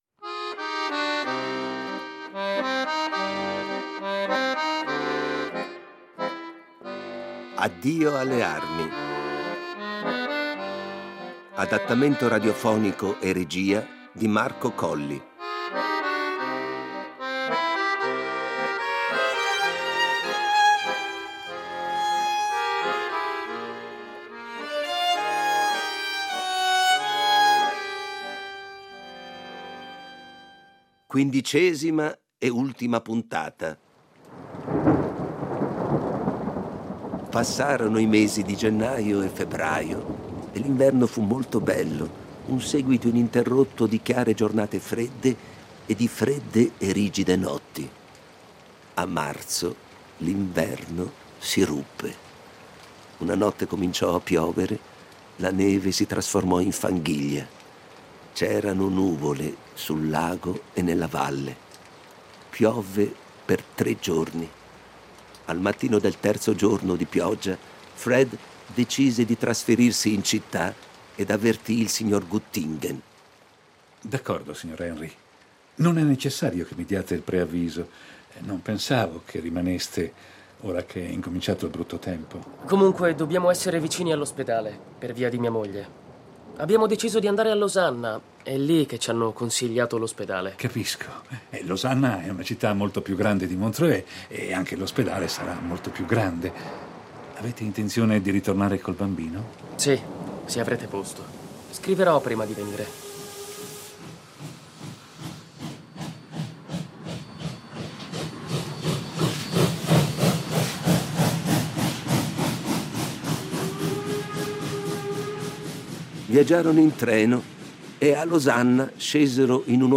Riduzione, adattamento radiofonico e regia